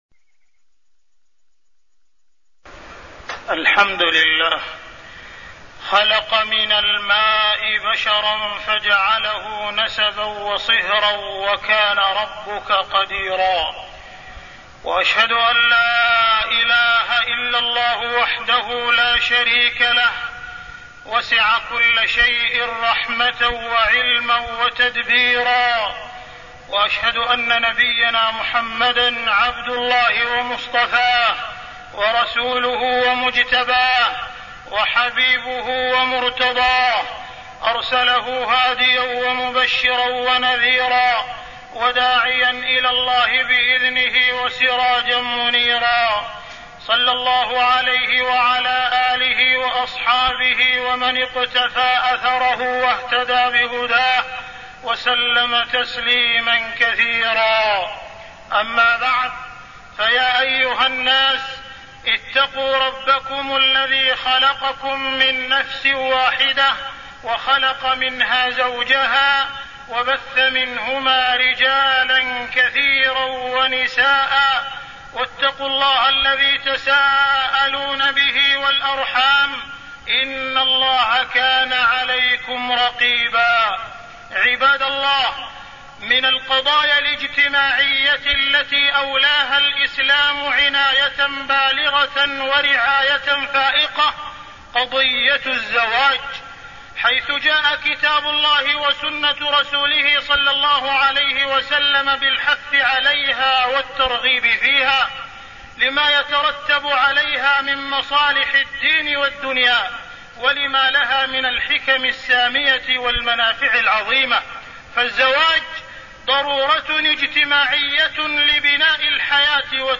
تاريخ النشر ٢٥ ربيع الأول ١٤٢٠ هـ المكان: المسجد الحرام الشيخ: معالي الشيخ أ.د. عبدالرحمن بن عبدالعزيز السديس معالي الشيخ أ.د. عبدالرحمن بن عبدالعزيز السديس قضية الزواج